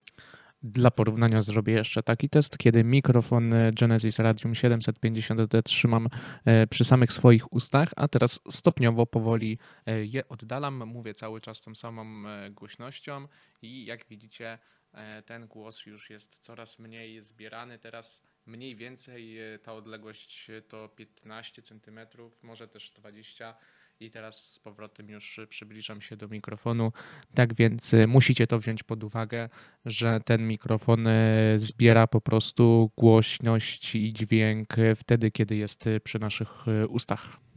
Genesis Radium 750 to mikrofon dynamiczny, jednokierunkowy, czyli kardioidalny.
Sam dźwięk przechwytywany przez Radium 750D jest czysty.
Nasz głos jest ponad wyraźny, a otoczenie praktycznie nieprzechwytywane.
Przykładowe próbki głosu prezentuje się następująco: